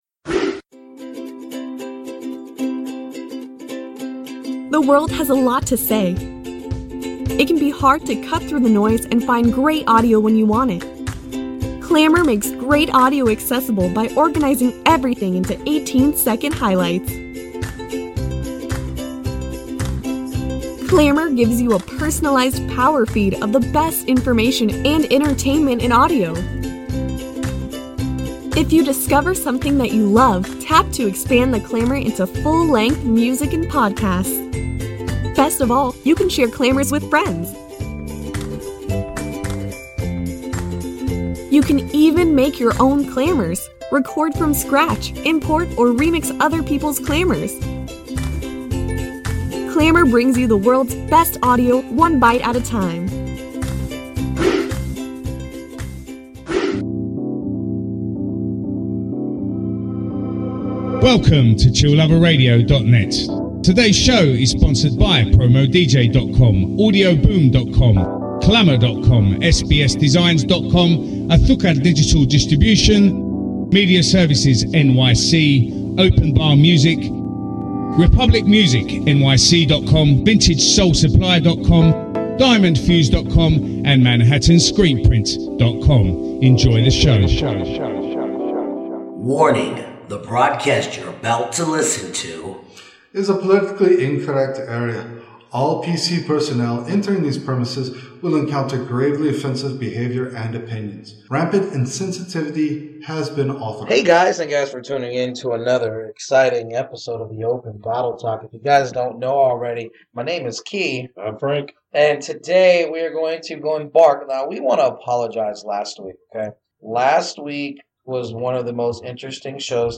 Styles: Beer Talk, Beer News, Beer, Talk Show